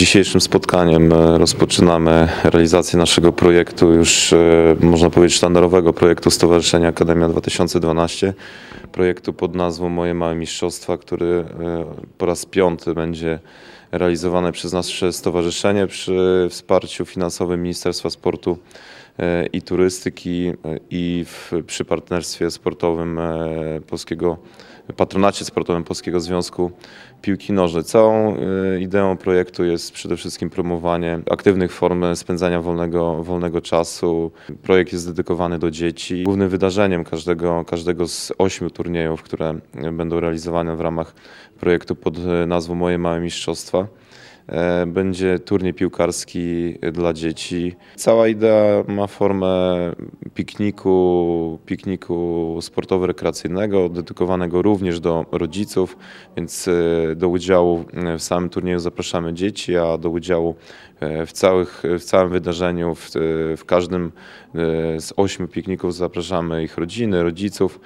Szczegóły przedstawił Wojciech Kowalewski, prezes Stowarzyszenia, były reprezentant Polski w piłce nożnej.